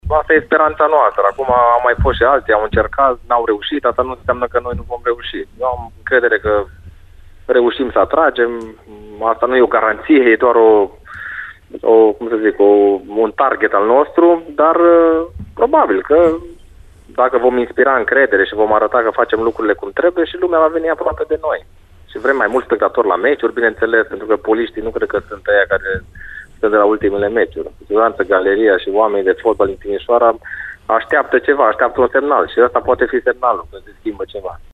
Noul director sportiv al divizionarei secunde de fotbal Politehnica Timișoara, Paul Codrea, a fost invitatul ediției de sâmbătă a emisiunii Arena Radio.